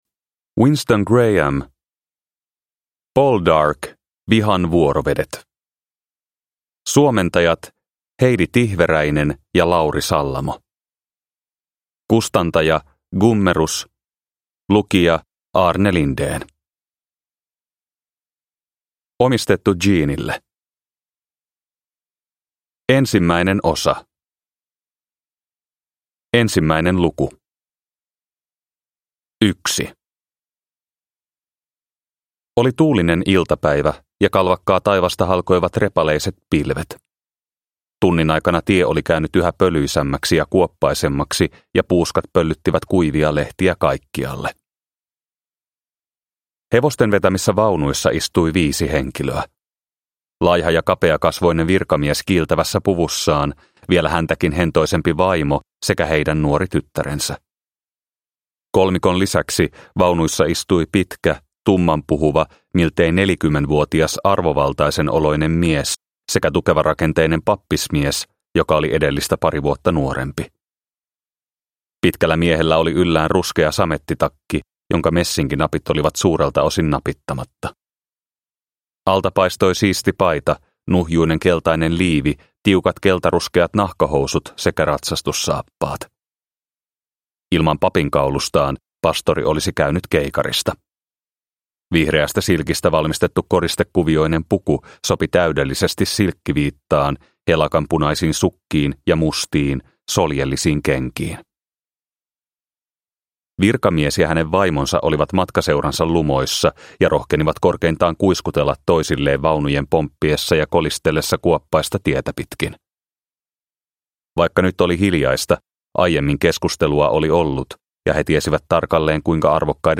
Poldark - Vihan vuorovedet (ljudbok) av Winston Graham